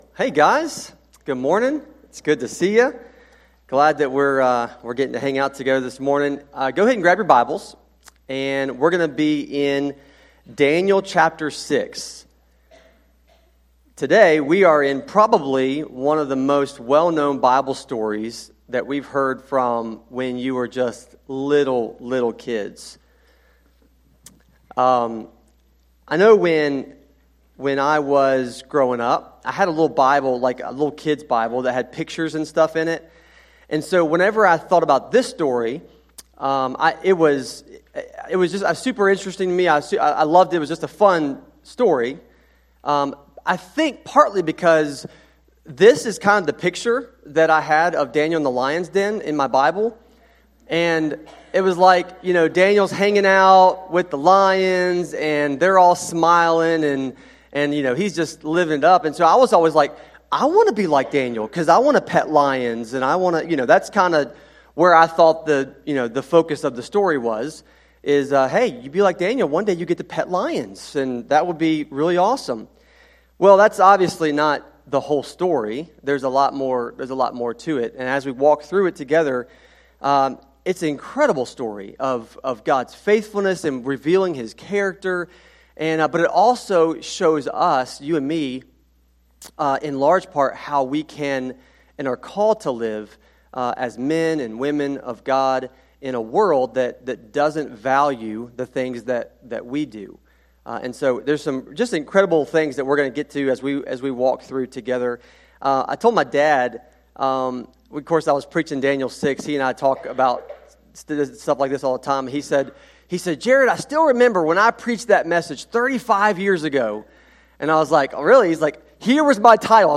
sermon-audio-trimmed-3.mp3